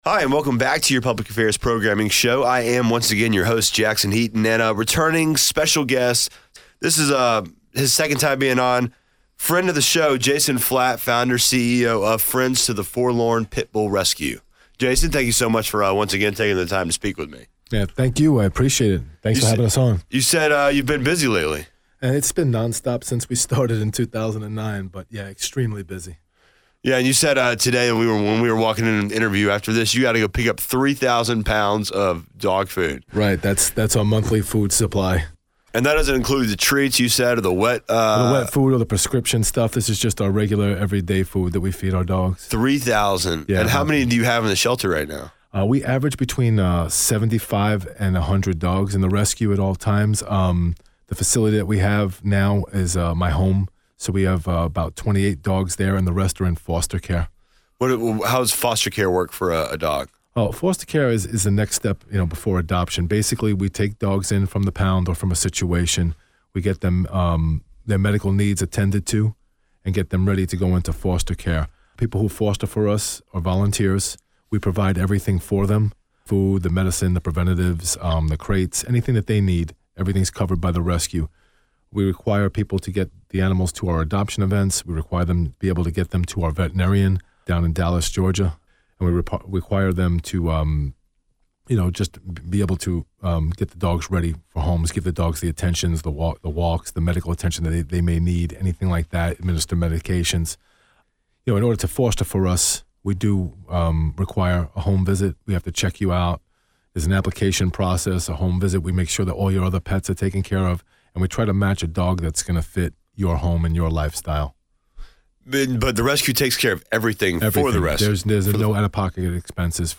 Rock 100.5 Interview 5.1.18 | Friends to the Forlorn Pitbull Rescue